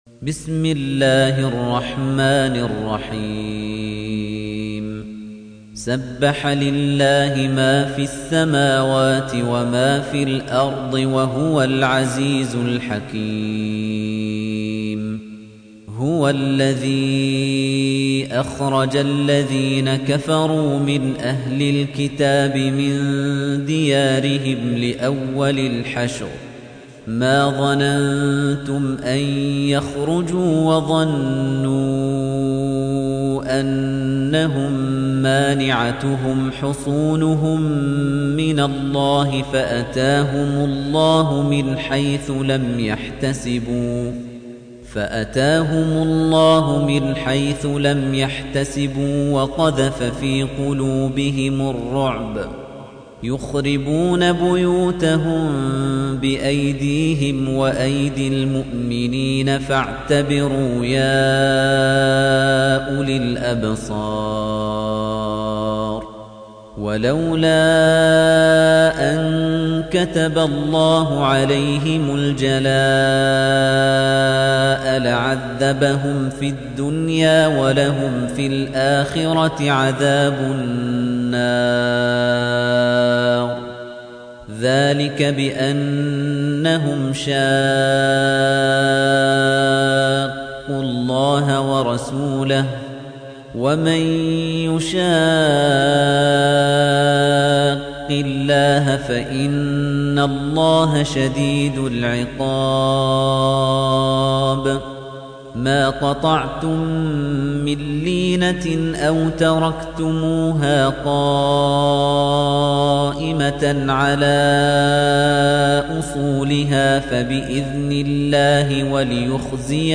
تحميل : 59. سورة الحشر / القارئ خليفة الطنيجي / القرآن الكريم / موقع يا حسين